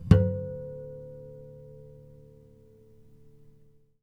harmonic-12.wav